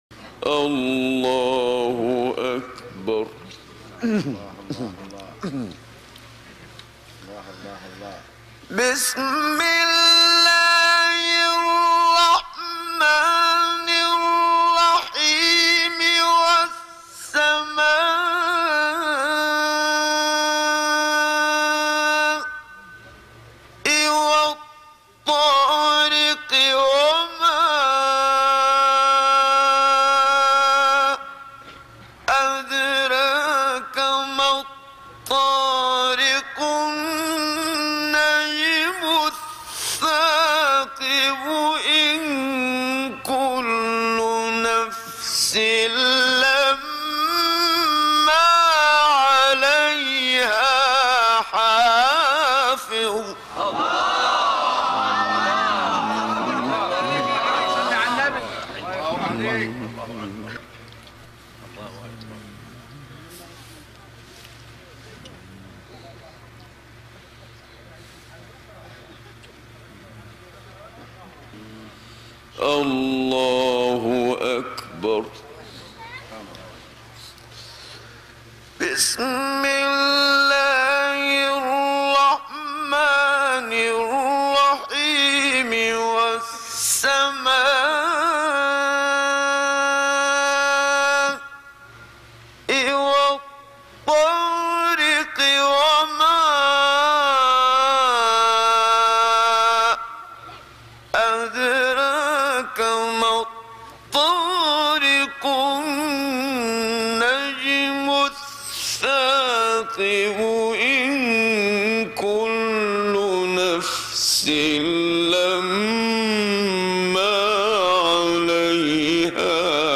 صوت | تلاوت کمتر شنیده شده از «محمدصدیق منشاوی»
تلاوت سوره مبارکه طارق با صدای محمدصدیق منشاوی (زاده ۲۰ ژانویه ۱۹۲۰ در منشاه – درگذشته ۲۰ ژوئن ۱۹۶۹ در قاهره)، قاری بنام جهان اسلام را به مناسبت سالروز وفات این قاری شهیر می‌شنوید.
با توجه به حزن خاصی که در صدای محمد صدیق منشاوی وجود داشت، به وی لقب «حنجره الباکیه» به معنی حنجره گریان را داده‌اند.